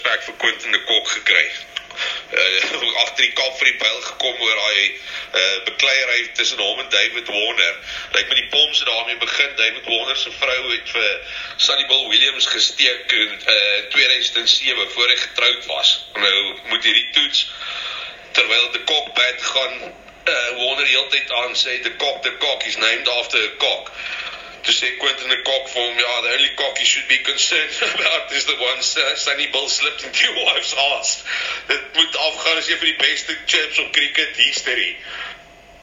Afrikaans sports radio
This is South Africa multi-lingual radio on the de Kock / Warner situation.